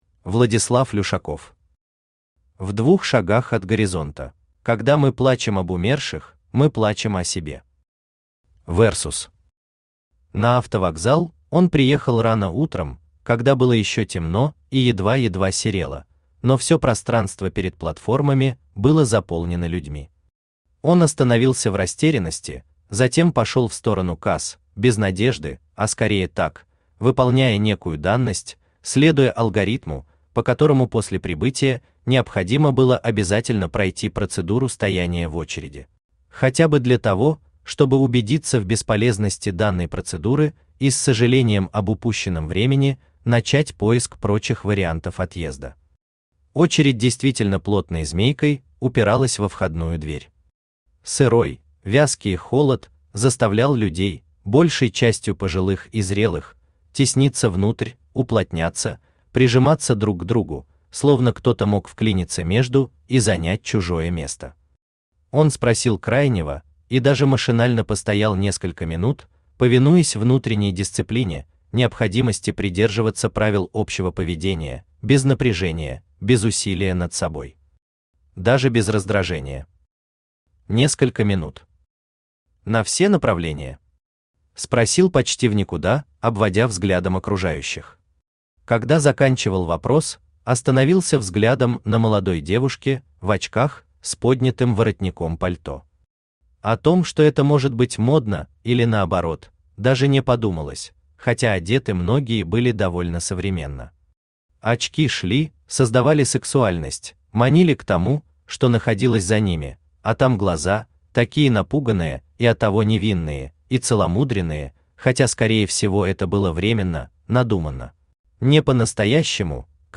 Аудиокнига В двух шагах от горизонта | Библиотека аудиокниг
Aудиокнига В двух шагах от горизонта Автор Владислав Люшаков Читает аудиокнигу Авточтец ЛитРес.